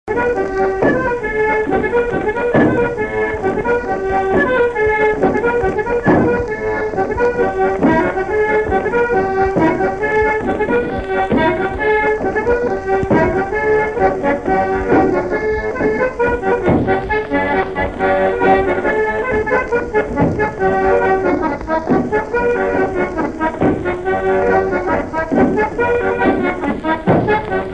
Branle
Sallertaine ( Plus d'informations sur Wikipedia ) Vendée
Résumé instrumental
danse : branle : courante, maraîchine ;
Pièce musicale inédite